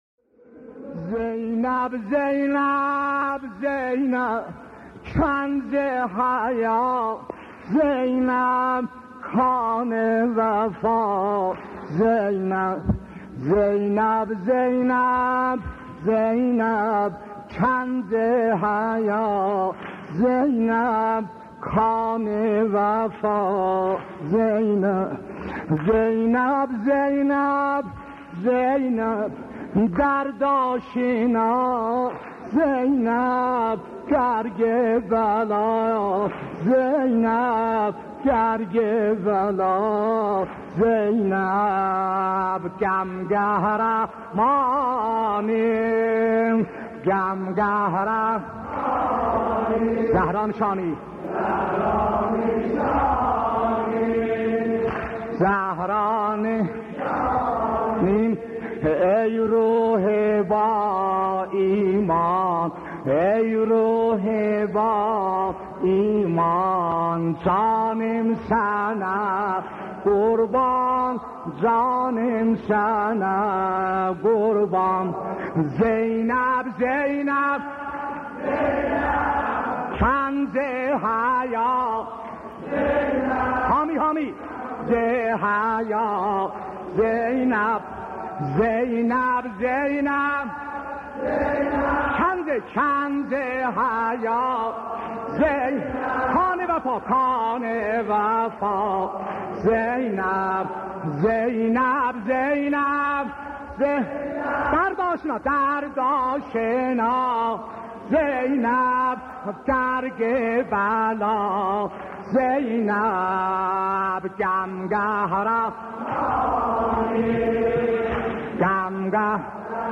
مداحی سلیم موذن زاده به مناسبت ایام عزاداری امام حسین (ع) در ماه محرم را بشنوید.